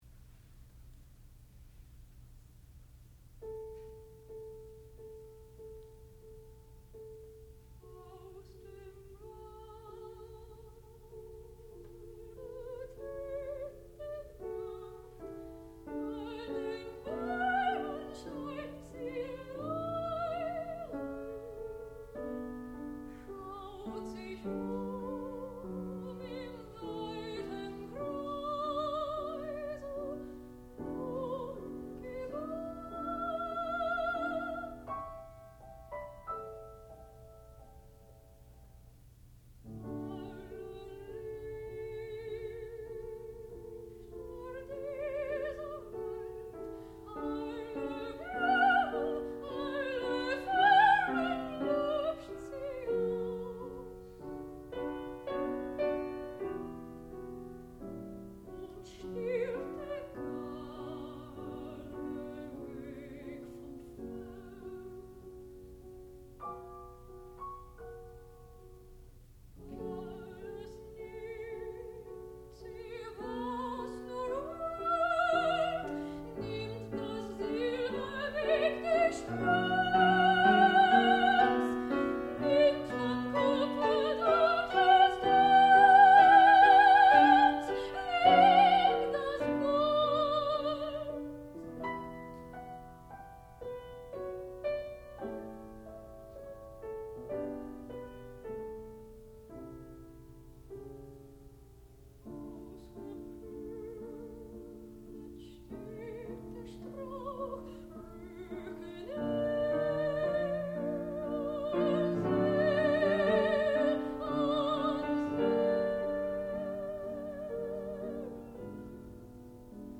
sound recording-musical
classical music
soprano
piano and harpsichord